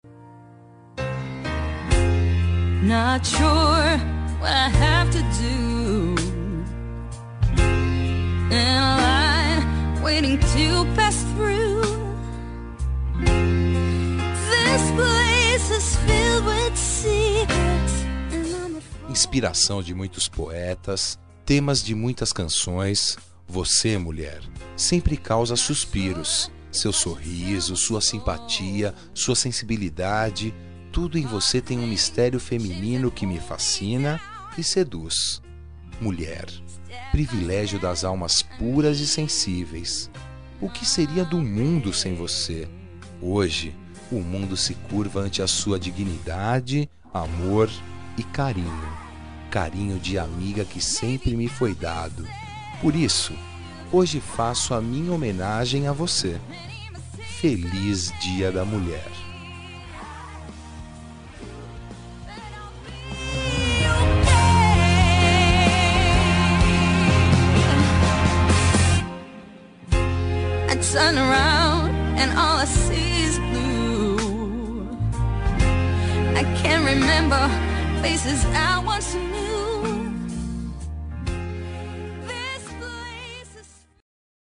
Dia das Mulheres Para Amiga – Voz Feminina – Cód: 5348